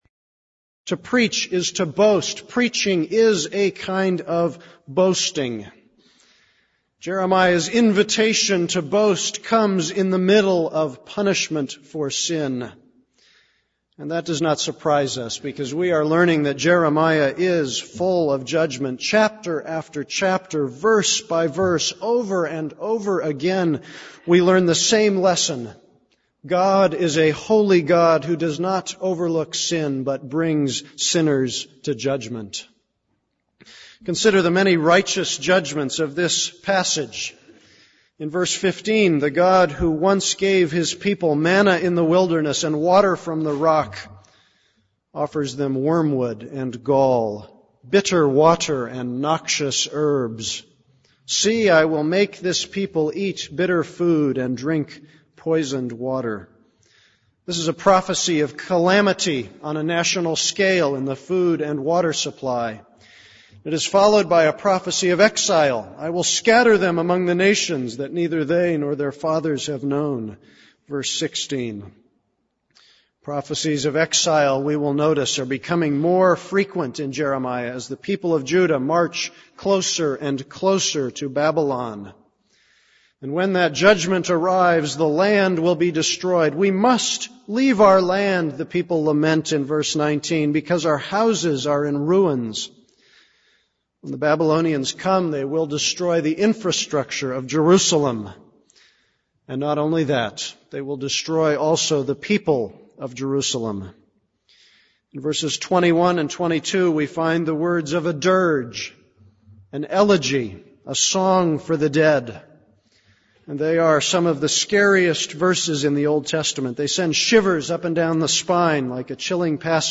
This is a sermon on Jeremiah 9:12-26.